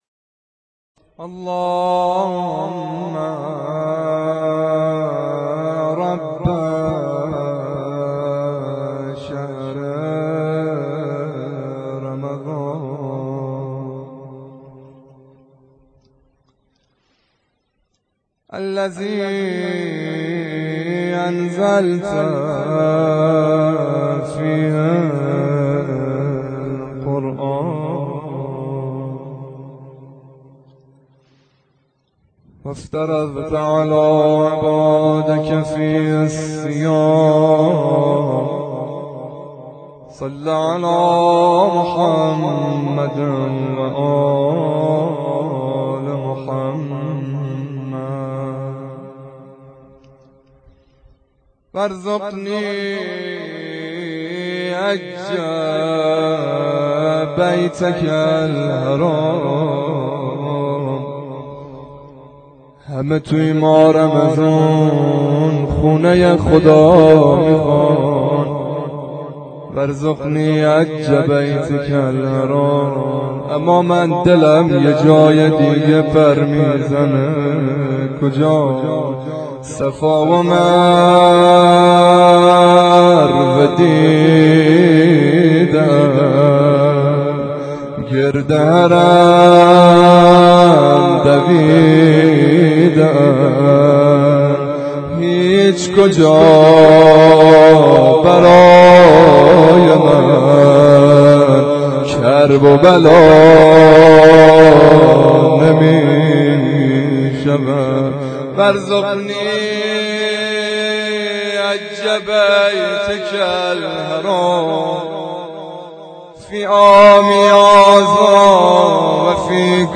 دعای قرآن و روضه حضرت علی _ شب 19 رمضان